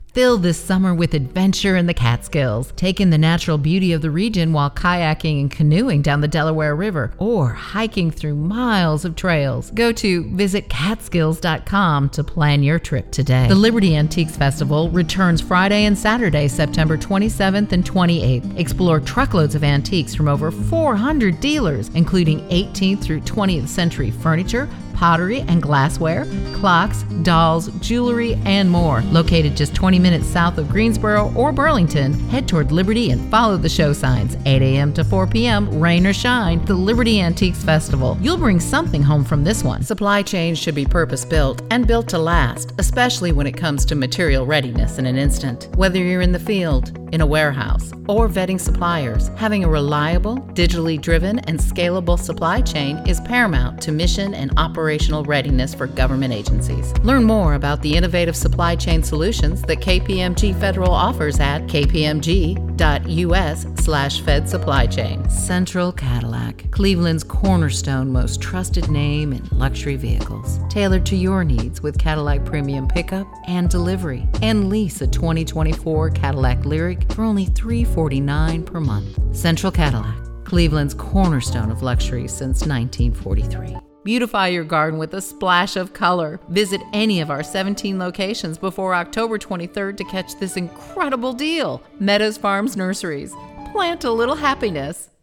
With her, you will find a voice full of personality that ranges from cheerful to dramatic to memorable characters.
Cool Gentle Kind Maternal Sultry Warm Wise
Adult Mature Adult
Commercial